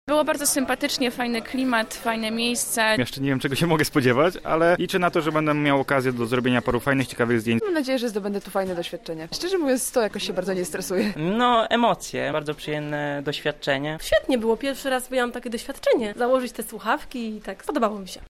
O wrażenia zapytaliśmy zarówno tych przed wejściem do studia, jak i po.